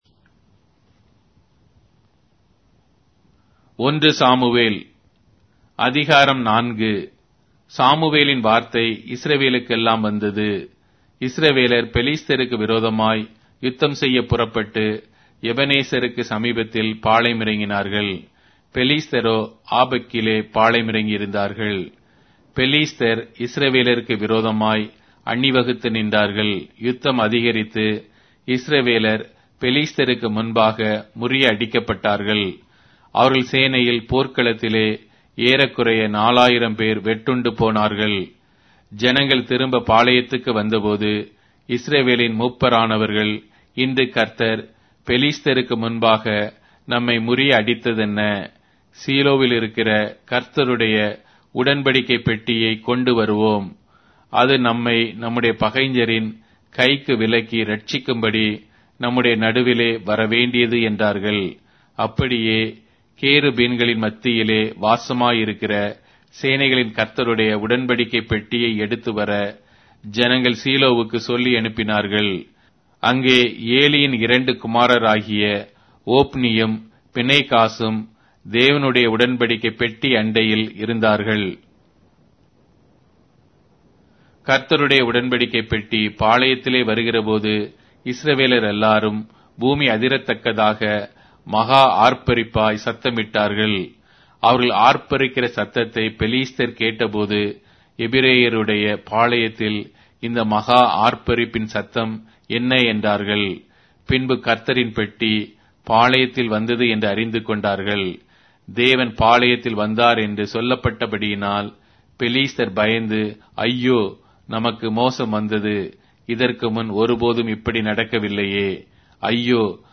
Tamil Audio Bible - 1-Samuel 23 in Tov bible version